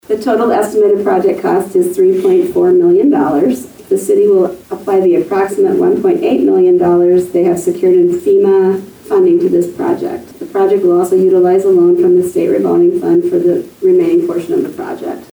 Humboldt, IA – There was a public hearing at Monday’s Humboldt City Council Meeting regarding the Humboldt Wastewater Treatment Plant Project.
Humboldt City Clerk Gloria Christensen outlined the total cost of the project and how it will be paid for.